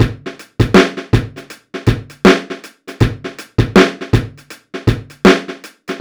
Index of /90_sSampleCDs/AKAI S6000 CD-ROM - Volume 4/Others-Loop/BPM_80_Others1